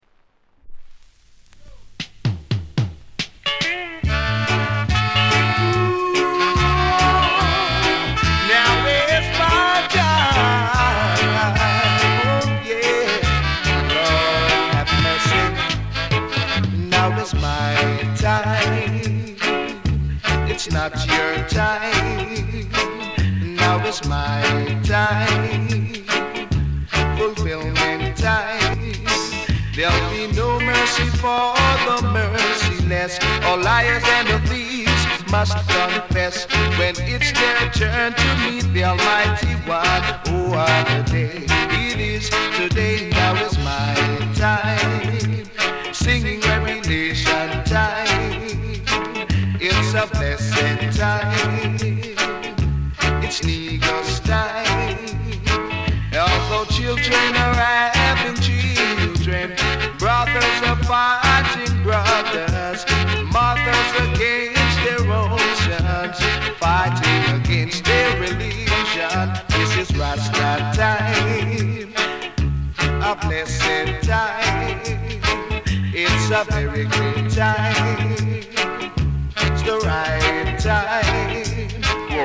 ジャマイカ盤 7inch/45s。
盤：EX-。大変良好です。薄いキズ or 擦れ 程度。大変キレイです！